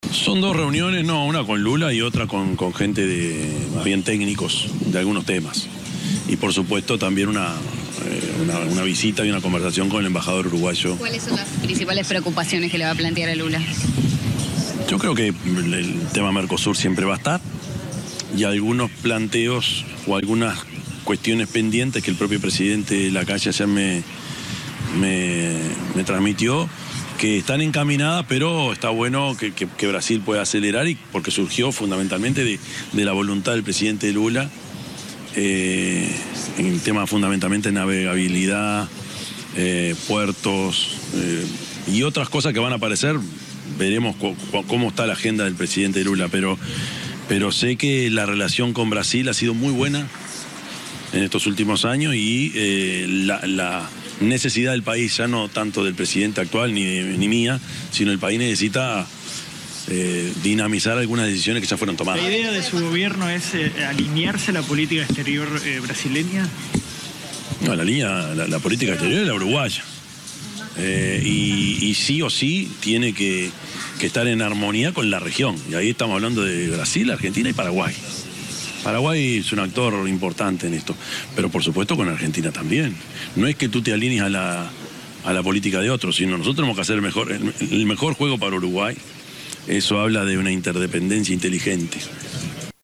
Orsi en rueda de prensa fue consultado sobre su viaje a Brasil.